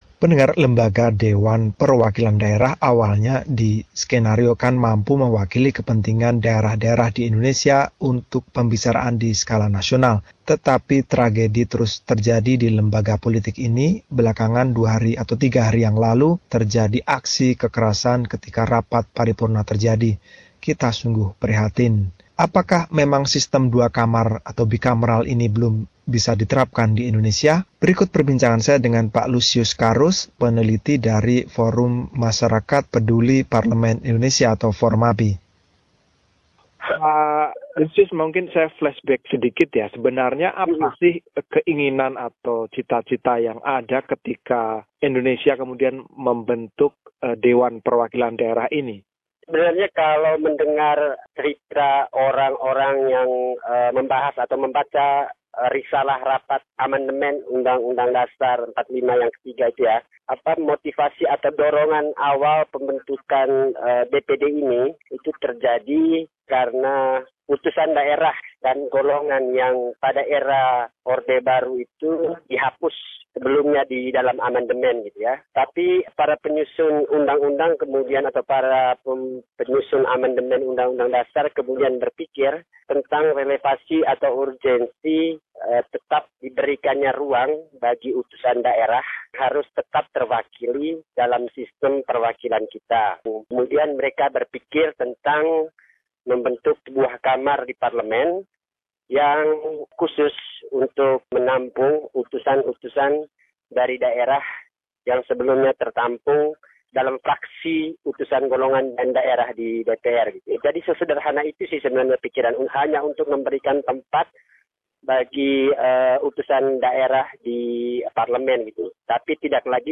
Wawancara